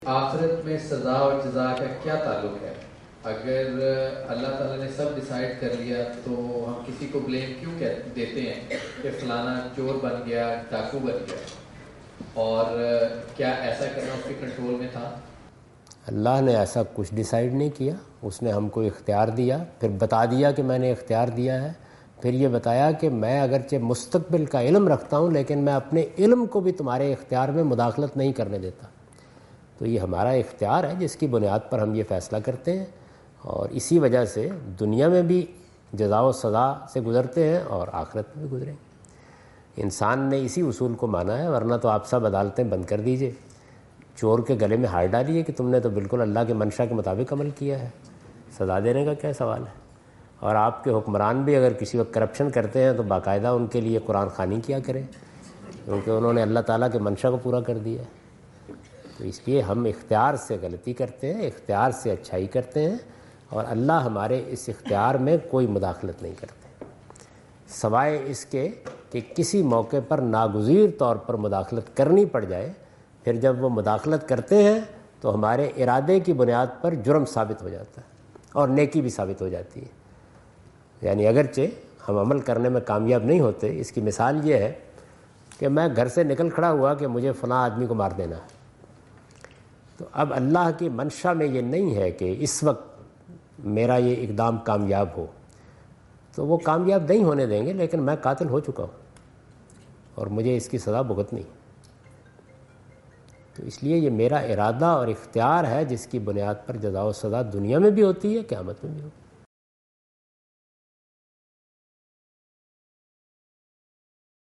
Javed Ahmad Ghamidi answer the question about "reward and punishment" in Macquarie Theatre, Macquarie University, Sydney Australia on 04th October 2015.
جاوید احمد غامدی اپنے دورہ آسٹریلیا کے دوران سڈنی میں میکوری یونیورسٹی میں "آخرت میں سزا و جزا" سے متعلق ایک سوال کا جواب دے رہے ہیں۔